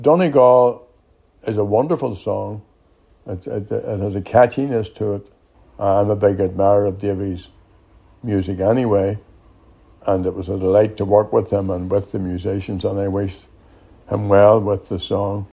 Speaking on his podcast, Gerry Adams says he was happy to help out a friend…………..